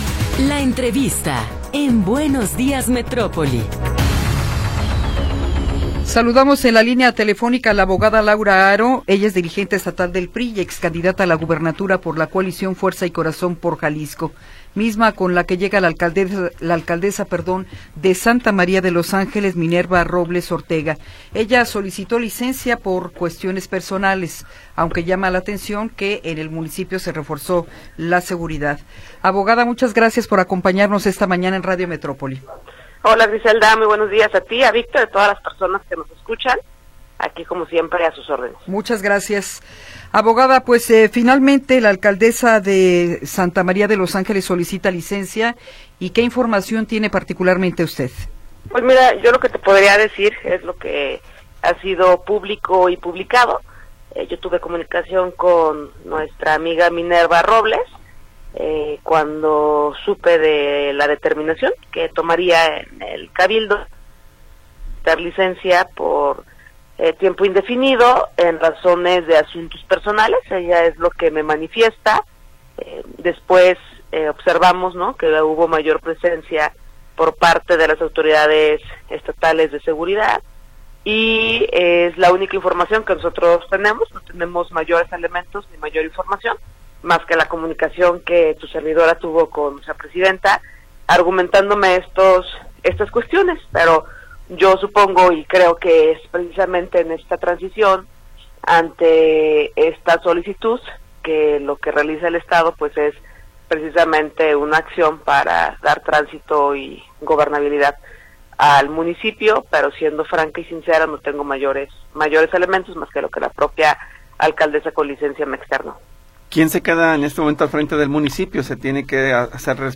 Entrevista con Laura Haro Ramírez
Laura Haro Ramírez, dirigente estatal del PRI, nos habla sobre la licencia al cargo que solicitó Minerva Robles, alcaldesa de Santa María de los Ángeles.